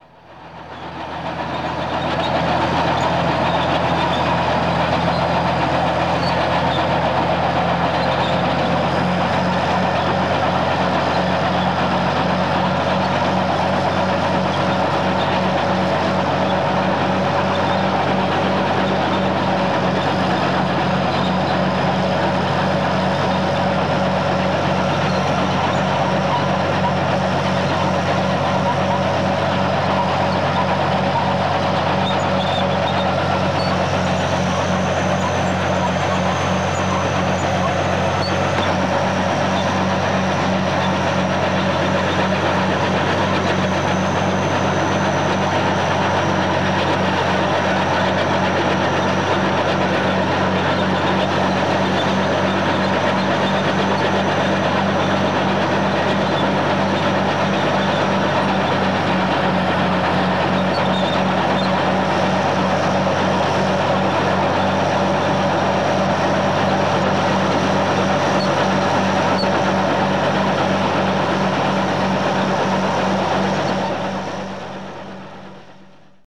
Звуки танка
Шум движения танка